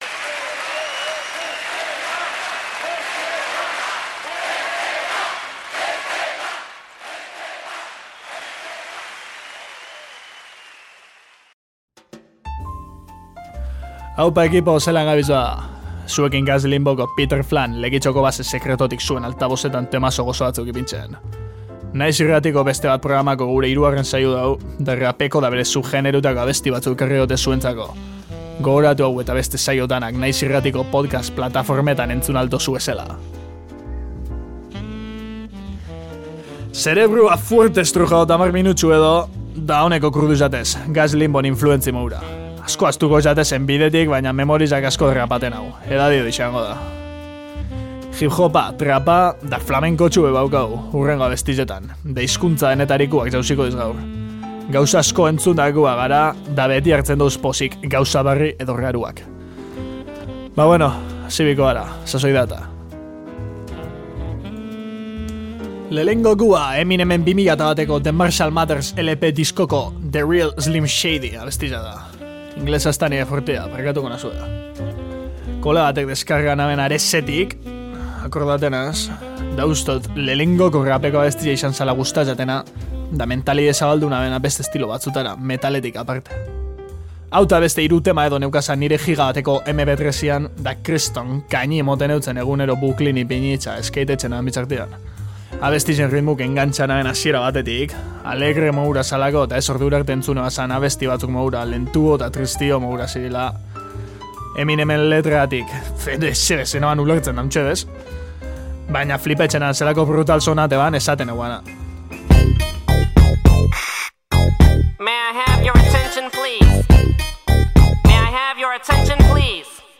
Horri metala gehitzen badiozu, G.A.Z. Limbokoek egin duten zerrenda osatzen da. Rap doinuak ekarri dituzte aste honetarako lekeitiarrek.